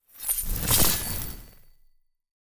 Free Frost Mage - SFX
frozen_armor_10.wav